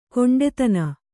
♪ koṇḍetana